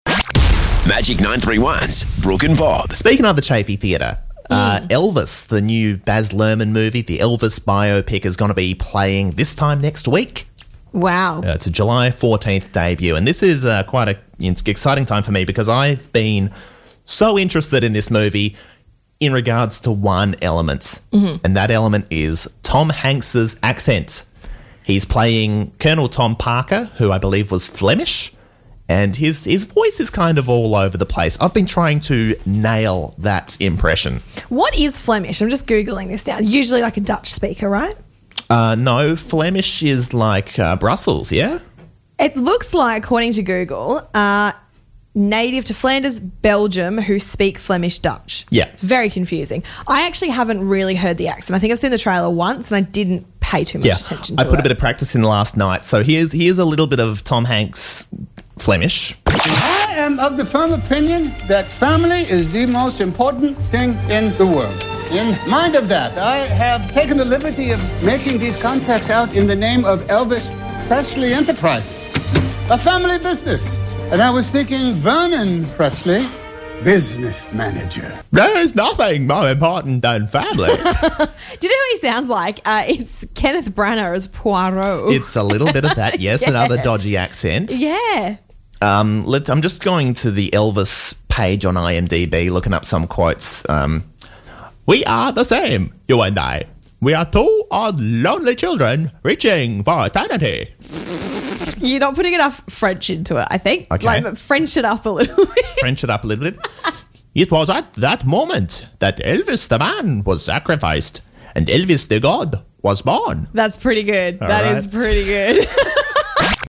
Impersonating Tom Hanks in "Elvis"
What is this accent?